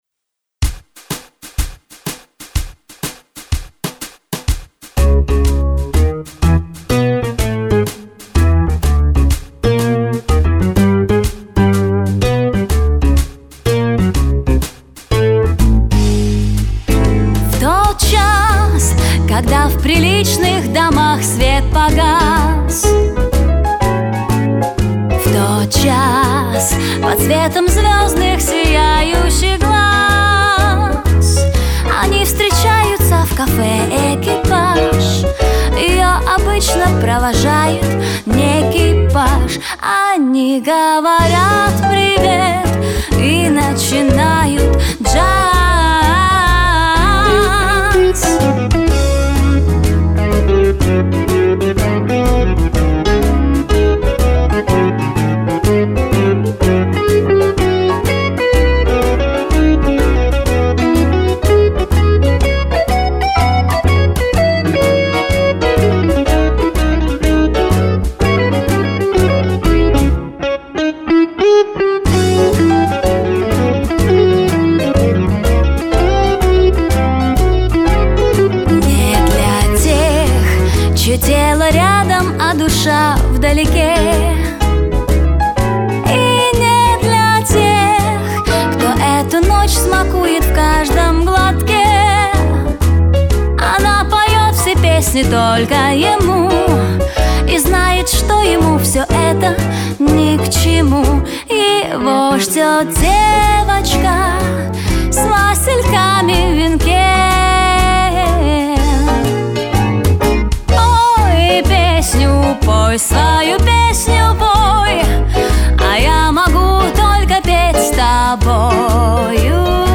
Здорово спели обе участницы!
Очень хорошо спели обе участницы.